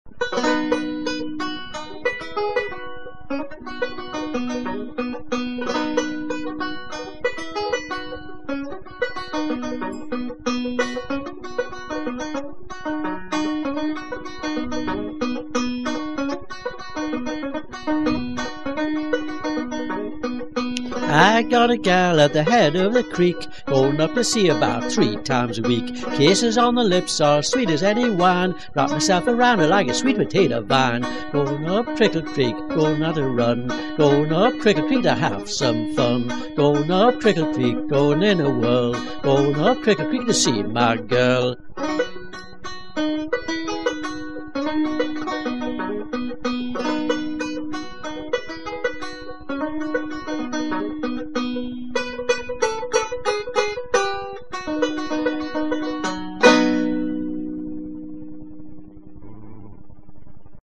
Years later (don't ask!) - and still loving that crisp, plinckety sound - playing the banjo and singing has almost become an obsession.
He is mostly into Old Time American folk music, and has collected a sizeable portfolio of Appalachian Mountain music, American Civil War songs, plantation songs and early Bluegrass material.